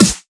Snare 3 (First Of the year).wav